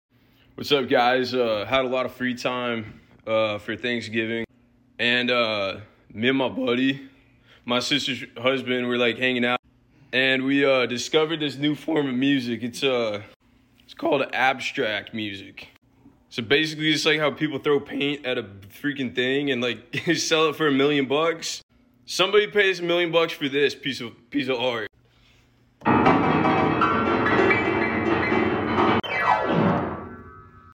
Just invented abstract music.